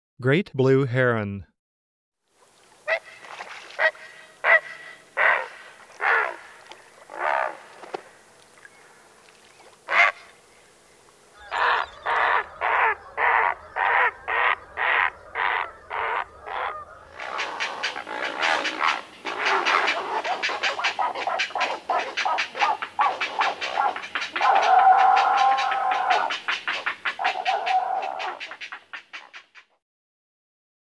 great_blue_heron.m4a